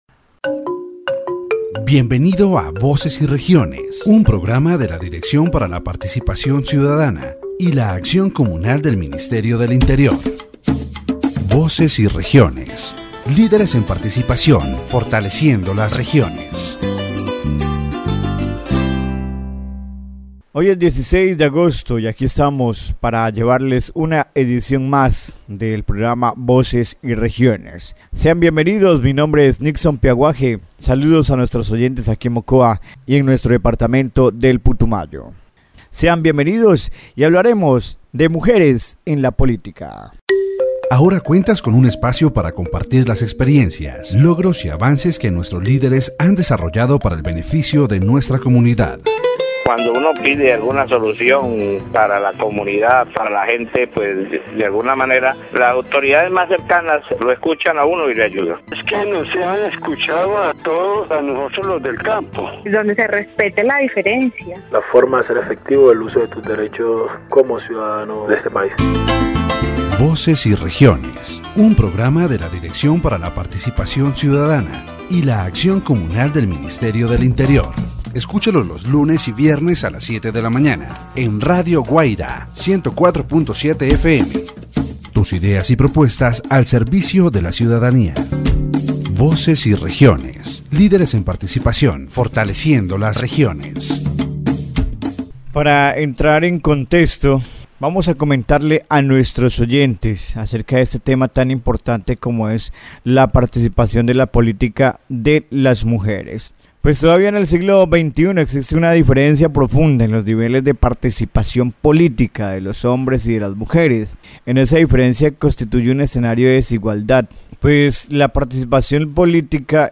The political participation of women in Colombia, with a special focus on the department of Putumayo. The radio program "Voces y Regiones" highlights the need to continue working to achieve gender equality in Colombian politics. The importance of implementing public policies that promote the participation of women and breaking gender stereotypes that limit their opportunities is highlighted.